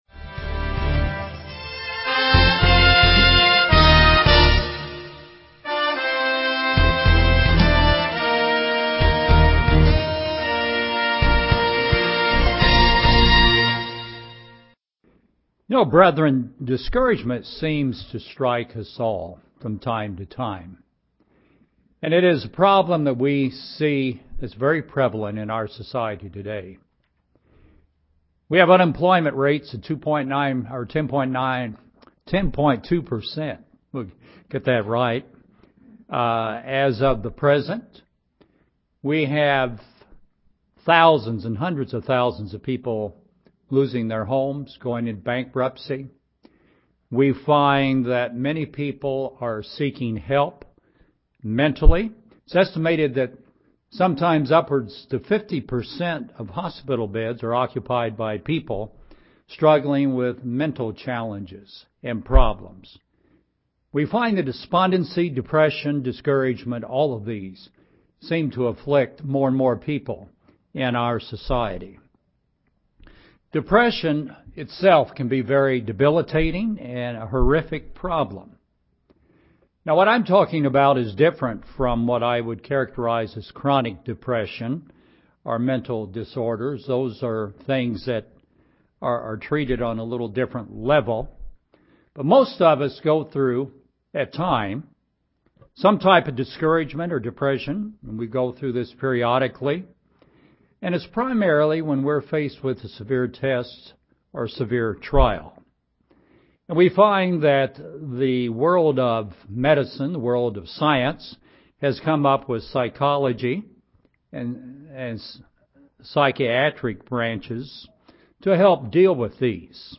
This sermon gives four points to help a christian deal with discouragement and depression.